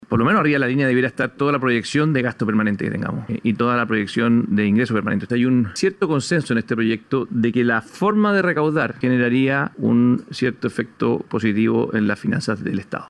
El senador de Evopoli Felipe Kast, dijo que algo básico en el financiamiento del proyecto, es que las proyecciones de gastos e ingreso estén bien aplicadas y reconoció que existe la posibilidad de que el Estado pueda recaudar recursos.